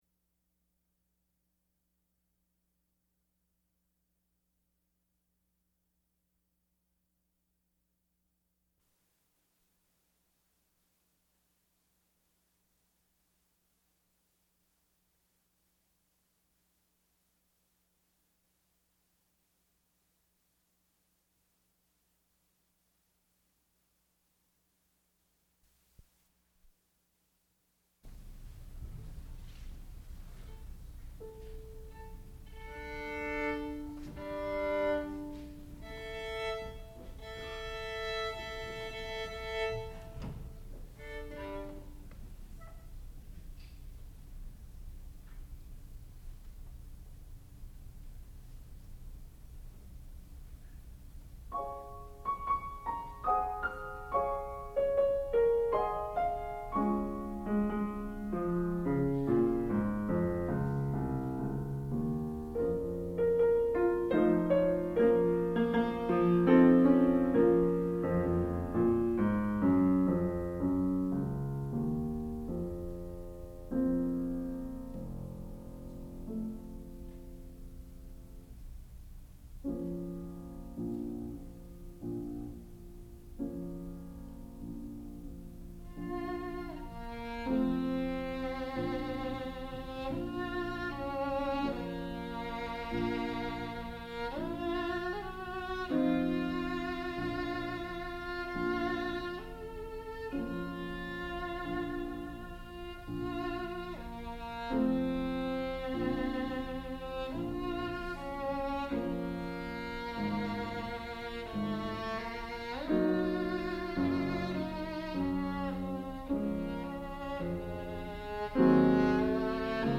sound recording-musical
classical music
Guest Artists
violin
piano